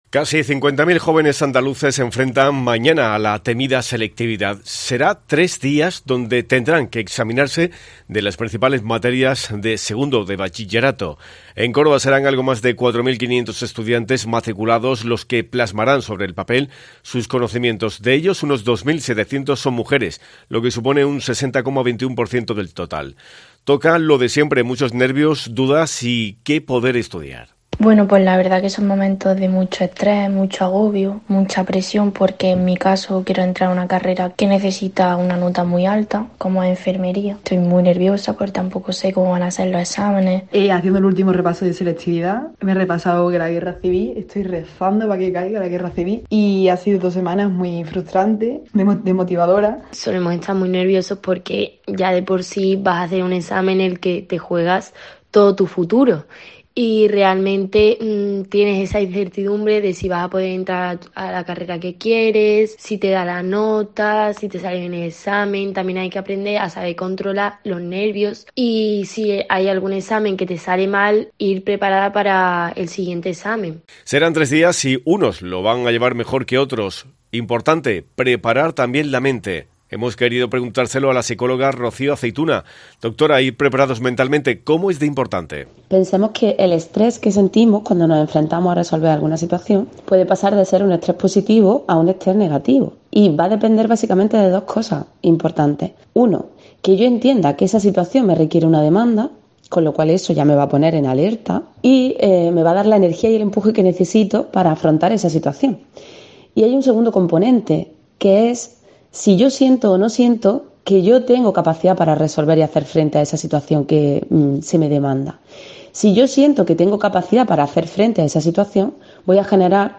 En Herrera en COPE hemos hablado con